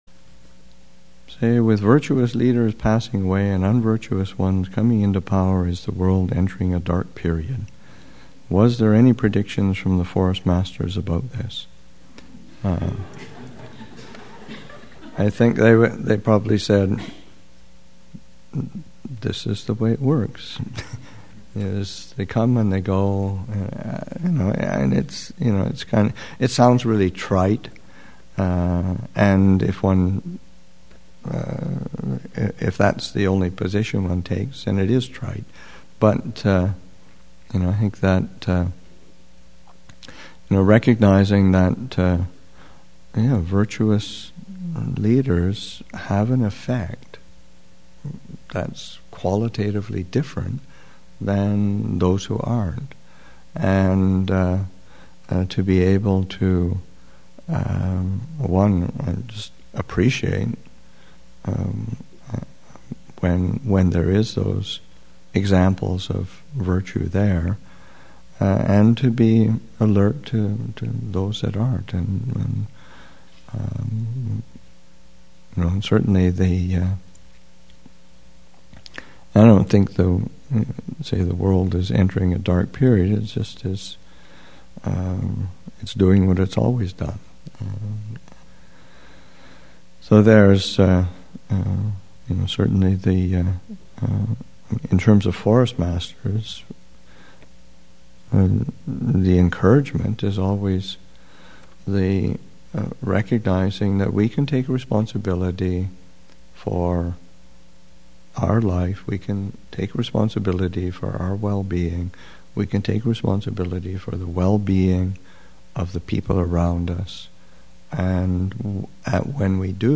Thanksgiving Retreat 2016, Session 2 – Nov. 20, 2016